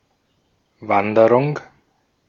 Ääntäminen
IPA : /wɔːk/